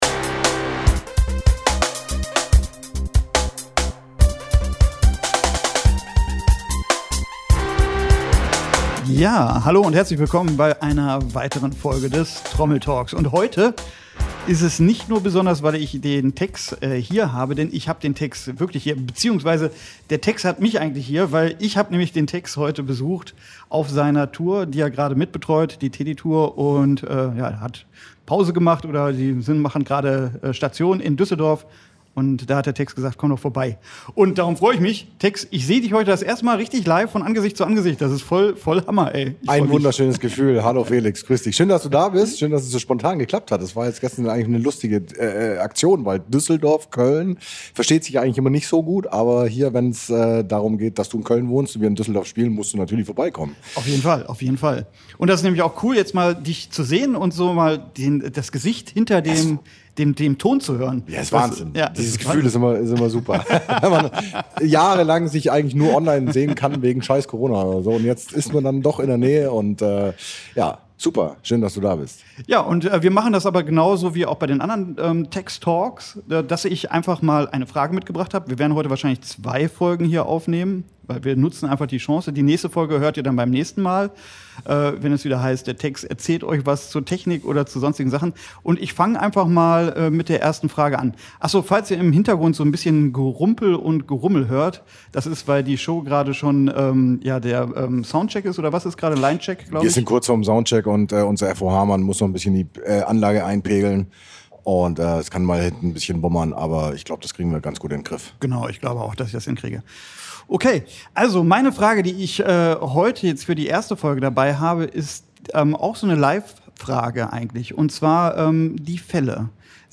Also habe ich mein Mikrofon eingepackt